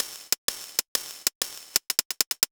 Index of /musicradar/ultimate-hihat-samples/95bpm
UHH_ElectroHatC_95-04.wav